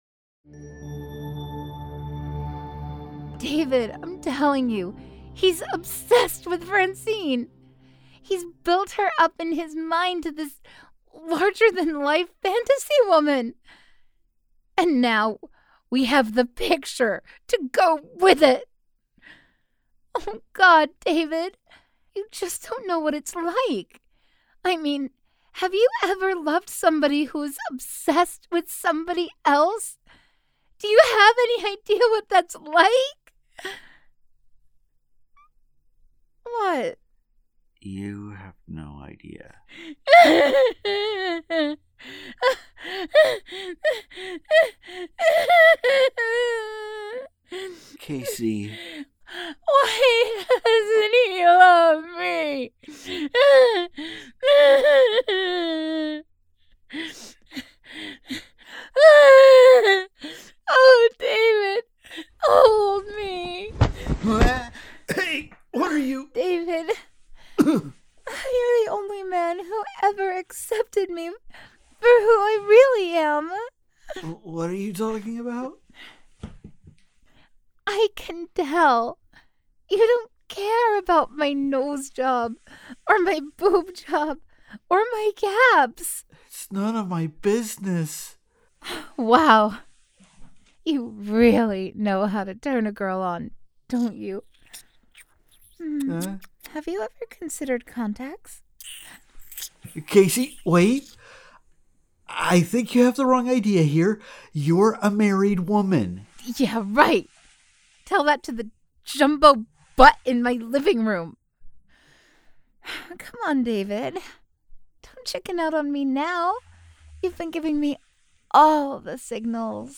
Strangers In Paradise – The Audio Drama – Book 7 – Episode 13 – Two True Freaks
The Ocadecagonagon Theater Group